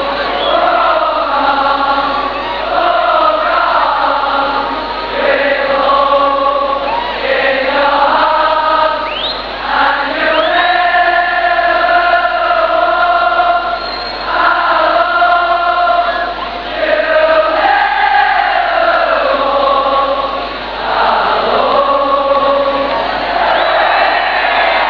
You'll never walk alone è il loro inno di battaglia.
kop_ynwa.wav